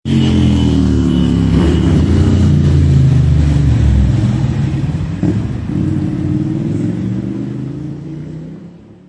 斩波器 启动和移出
描述：哈雷戴维森的深沉鸣叫...
标签： 自行车 斩波器 戴维森 引擎 哈雷 摩托车 交通
声道立体声